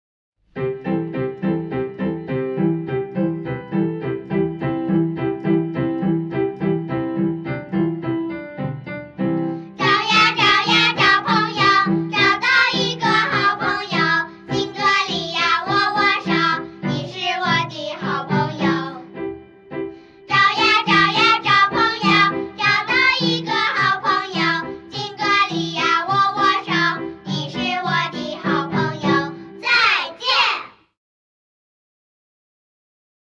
14 Song - Find A Friend